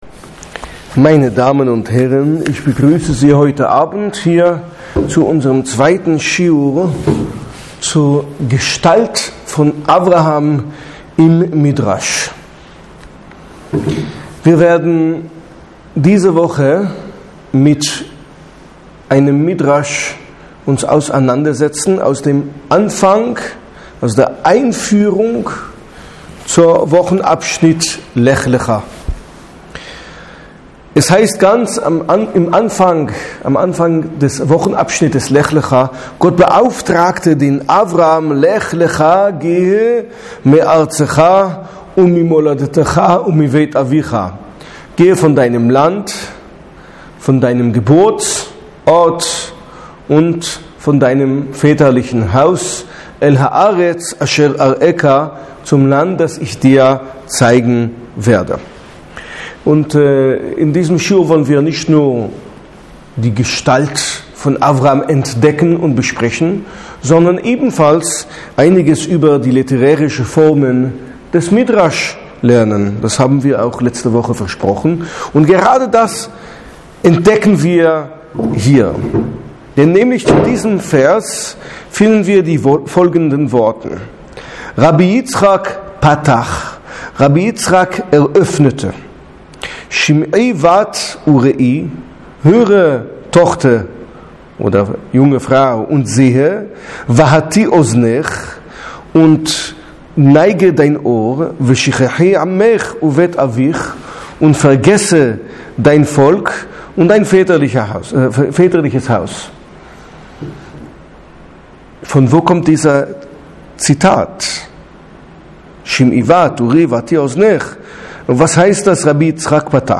Die Gestalt von Abraham im Midrasch – Schi’ur #2 (Audio)